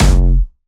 Jumpstyle Kick 1